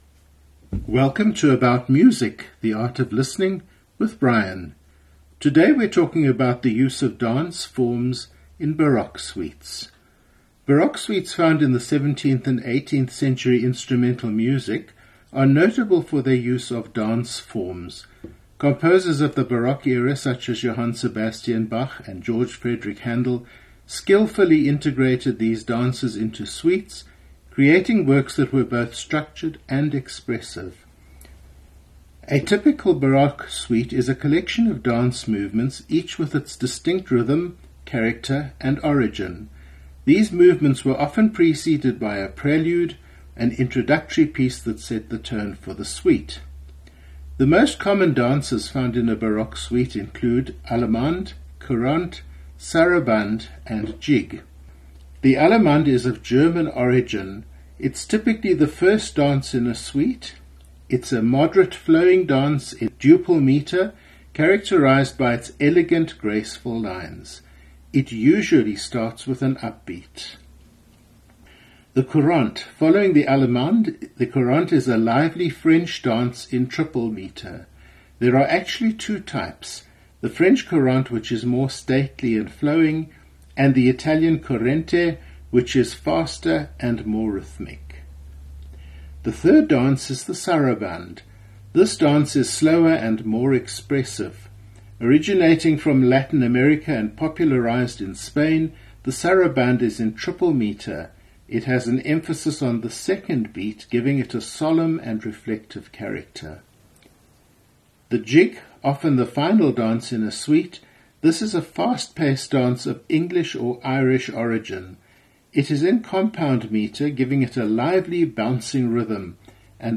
Today’s programme is part 1 of 3 in which we look at the structure called the Suite, which was an instrumental staple of the Baroque period, and a forerunner of the Classical symphony. The Suite is based on stylized dances of the period (or earlier), and consequently are quite easy listening.